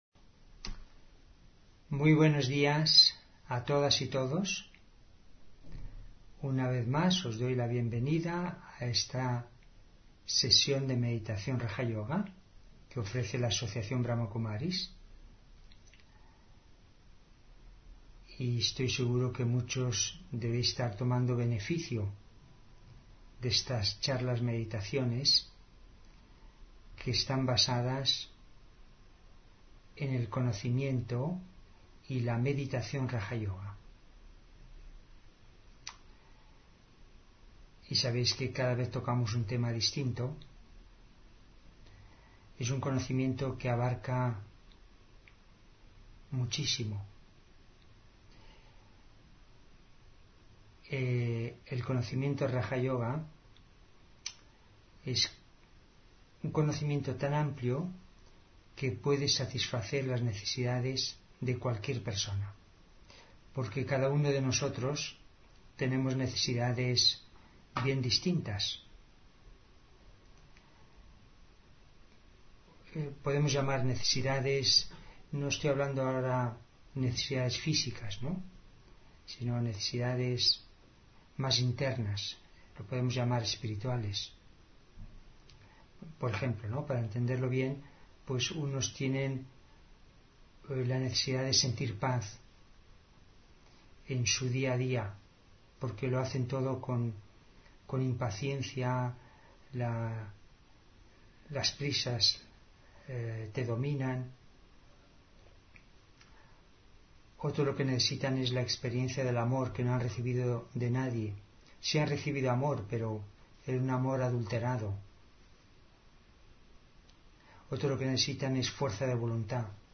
Meditación de la mañana: Ser como un árbol cargado de frutas